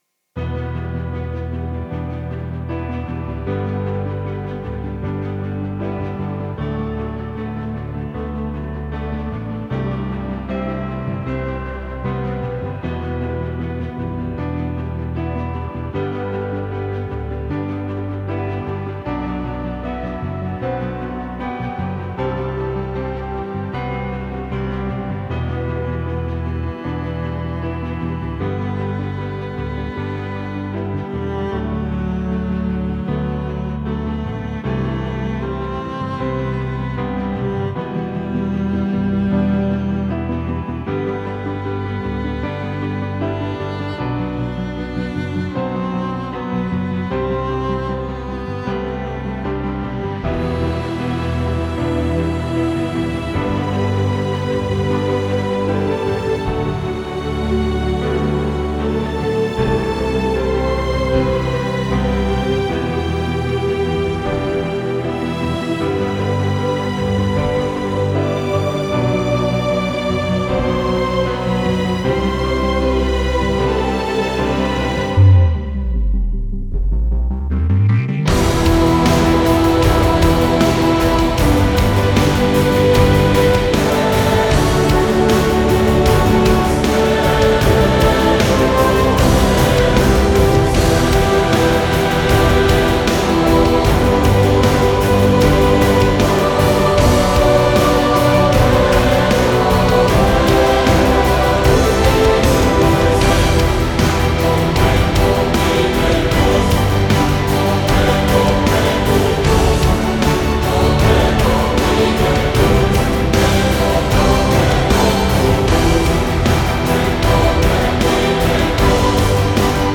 史诗气势音乐